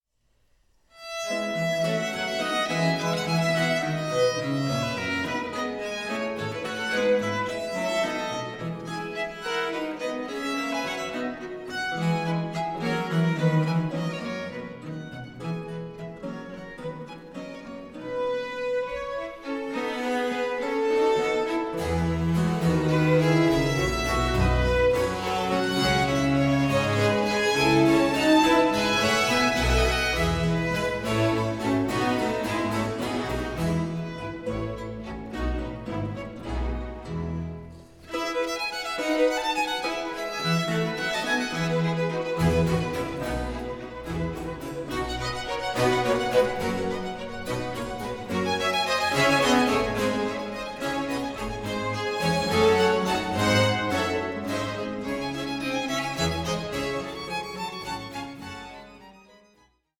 Concerto grosso in F Major, Op. 6 No. 6
Largo 2:53